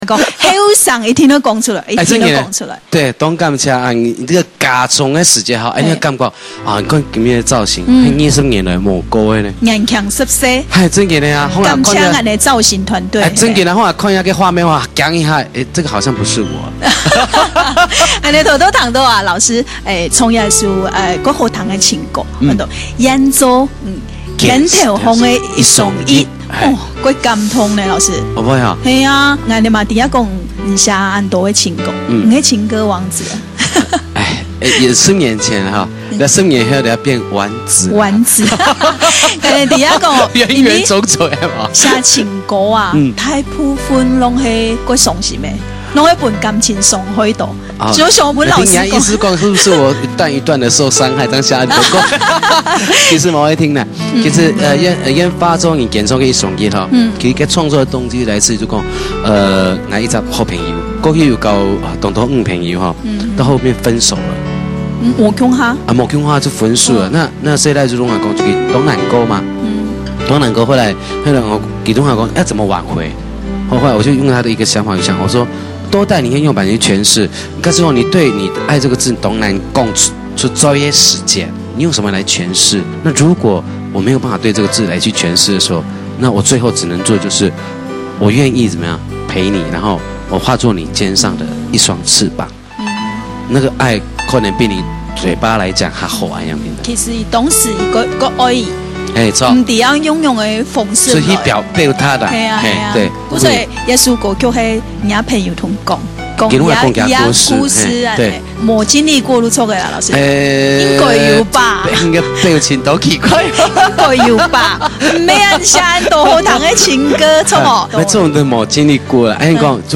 专访